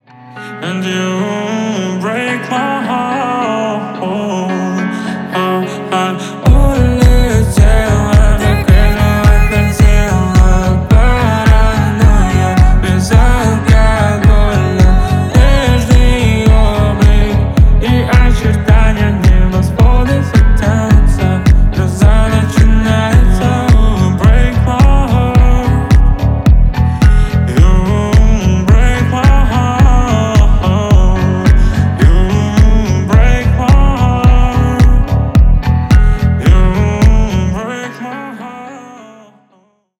бесплатный рингтон в виде самого яркого фрагмента из песни
Поп Музыка
тихие